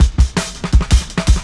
Shuffling Live Cut 1.wav